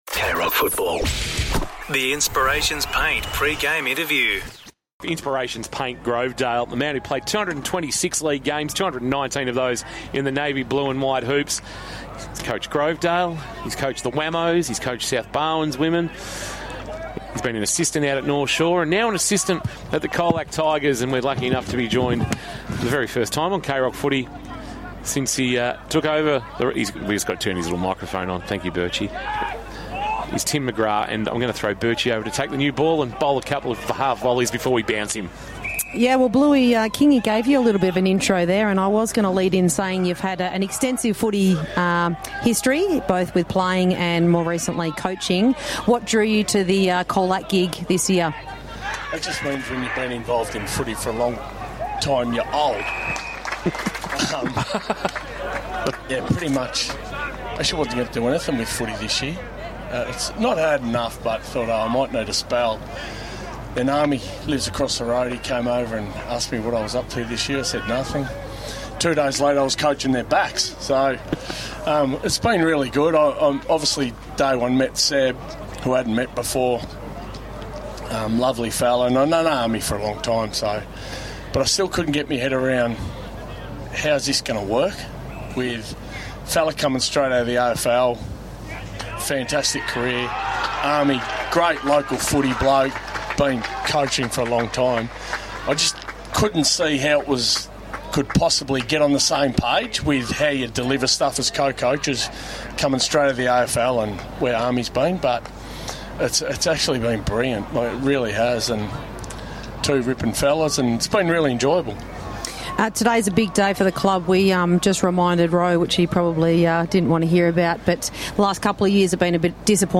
2025 - GFNL - Qualifying Final - Newtown & Chilwell vs. Colac - Pre-match interview